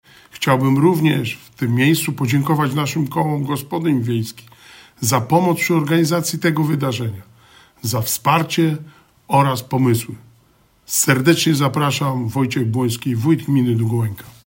Zaprasza Wojciech Błoński, Wójt Gminy Długołęka.
– Po kilkuletniej przerwie spowodowanej pandemią, wracamy z tym wyjątkowym wydarzeniem – mówi Wojciech Błoński.